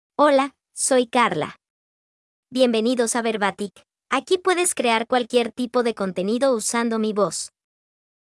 FemaleSpanish (Honduras)
Karla is a female AI voice for Spanish (Honduras).
Voice sample
Female
Karla delivers clear pronunciation with authentic Honduras Spanish intonation, making your content sound professionally produced.